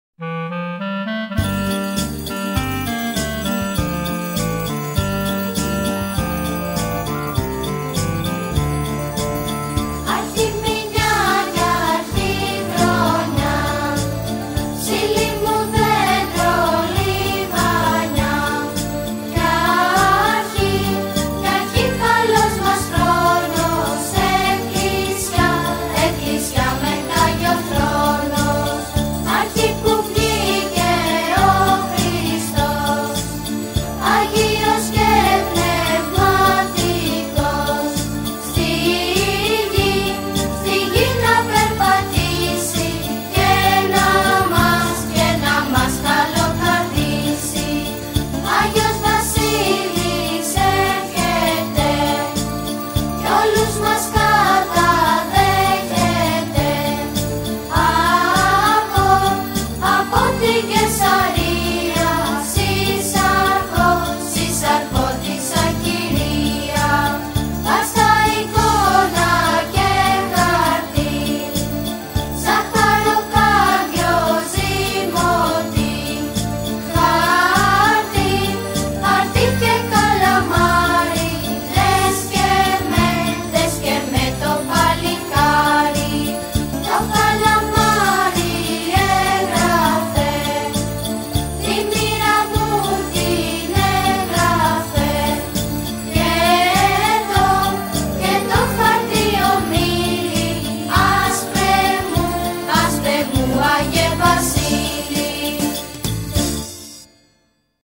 NewYearCarols.mp3